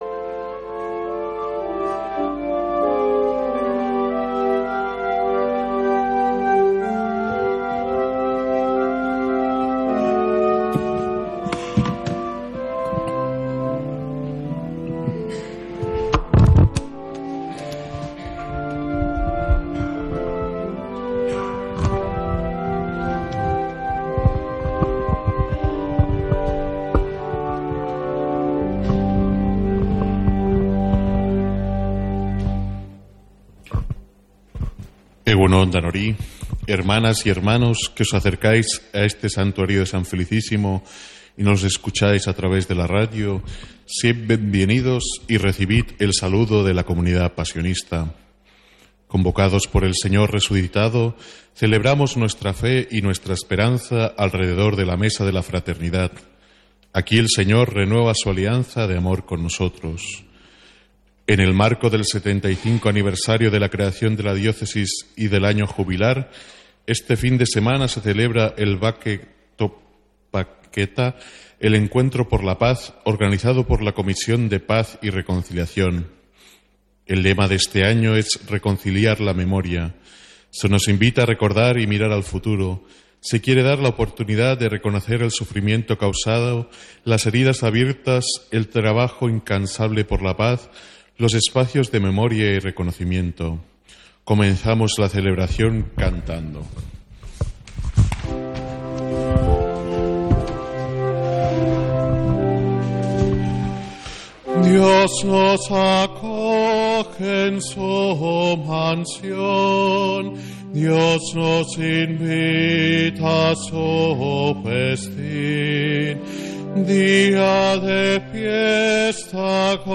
Santa Misa desde San Felicísimo en Deusto, domingo 23 de febrero